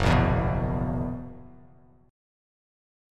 F#11 chord